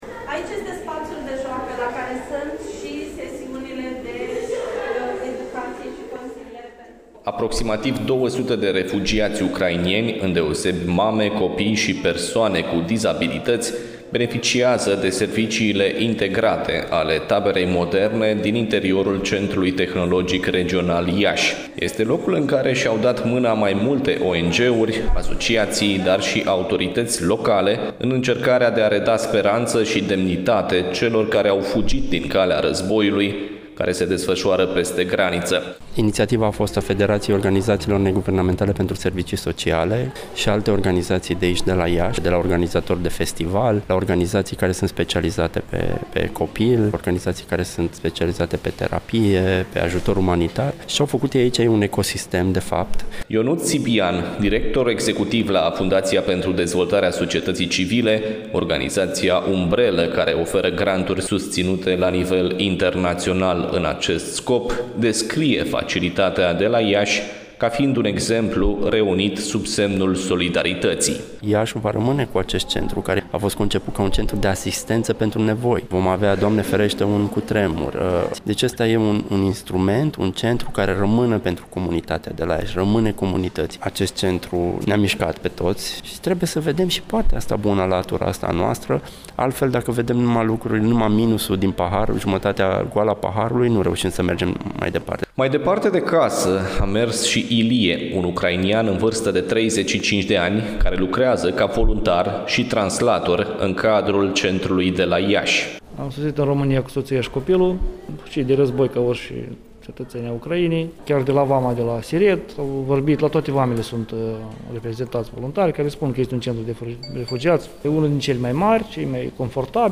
Reportajul în variantă audio: